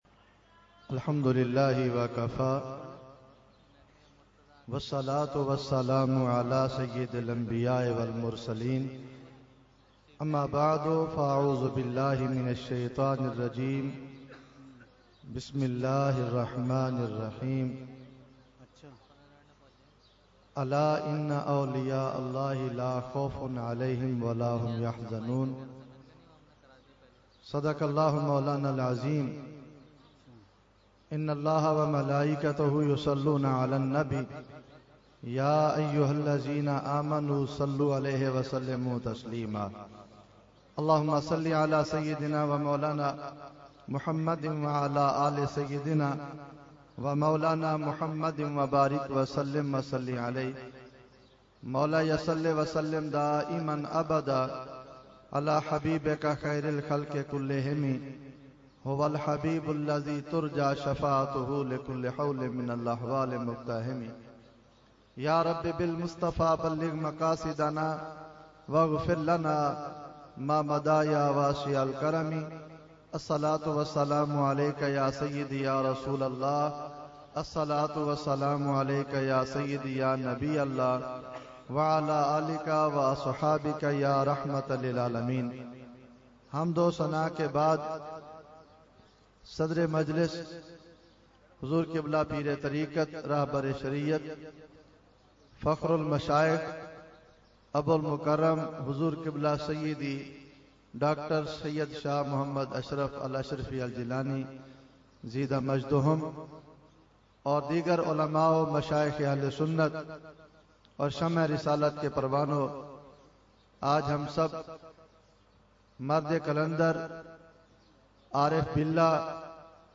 Mediaa: Urs Qutbe Rabbani 2018
Category : Speech | Language : UrduEvent : Urs Qutbe Rabbani 2018